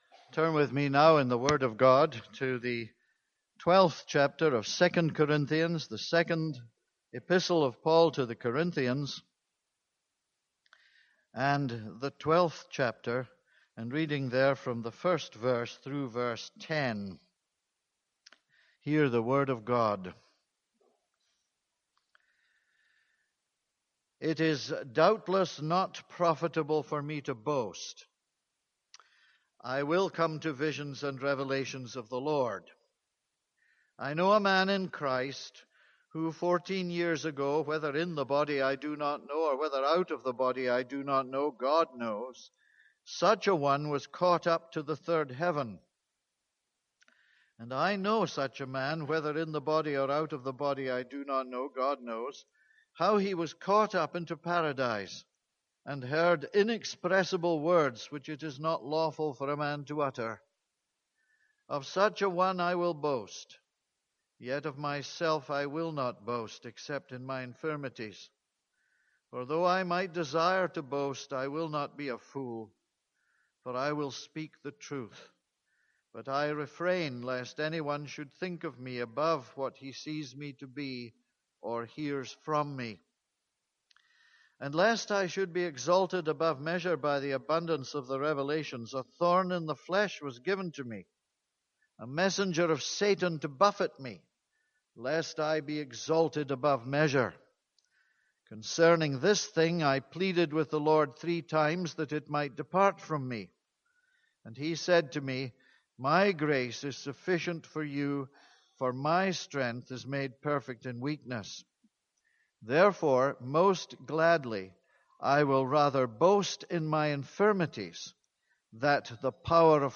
This is a sermon on 2 Corinthians 12:1-10.